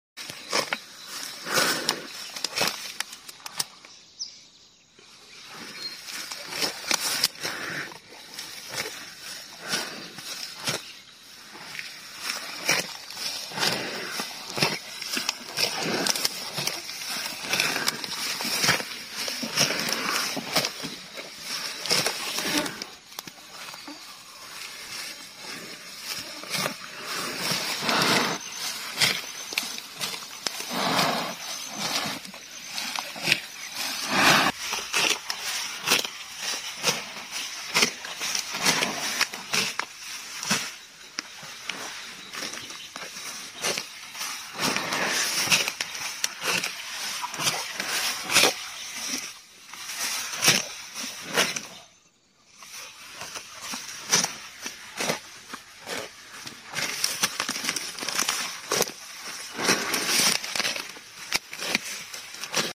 Grass Sound Effects Free Download.